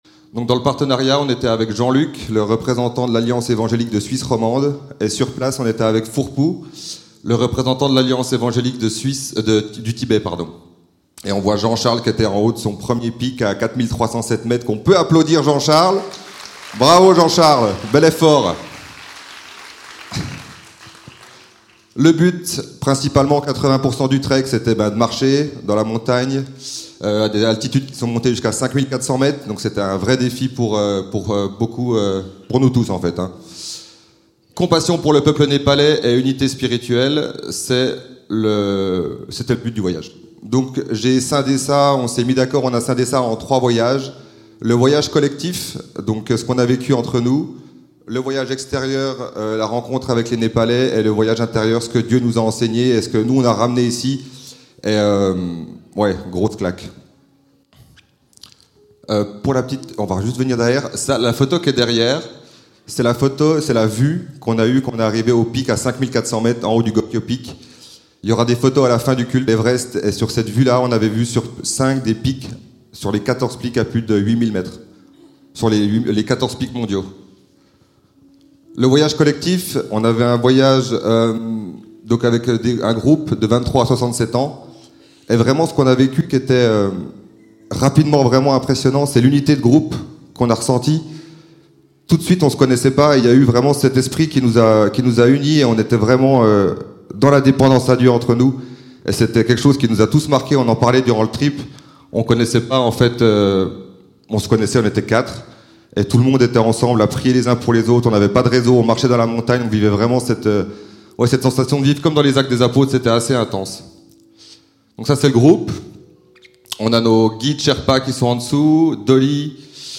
Témoignage : Voyage au Népal — Prédication : Soyons vigilants
Type De Service: Prédication